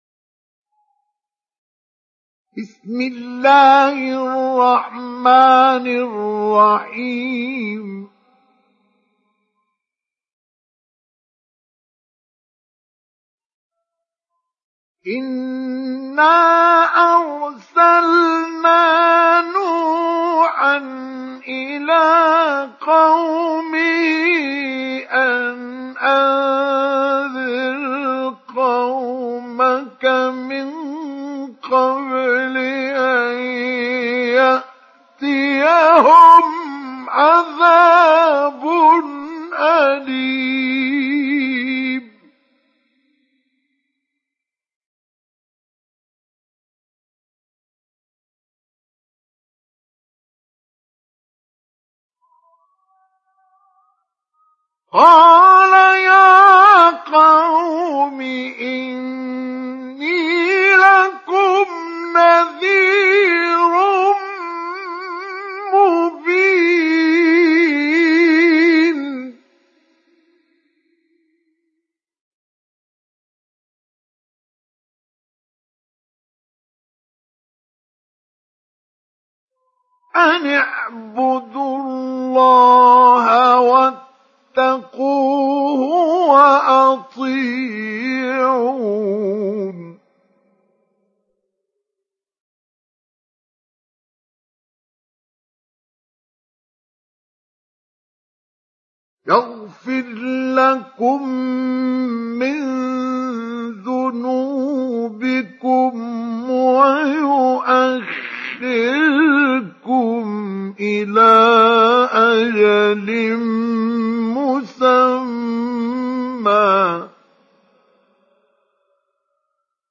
تحميل سورة نوح mp3 بصوت مصطفى إسماعيل مجود برواية حفص عن عاصم, تحميل استماع القرآن الكريم على الجوال mp3 كاملا بروابط مباشرة وسريعة
تحميل سورة نوح مصطفى إسماعيل مجود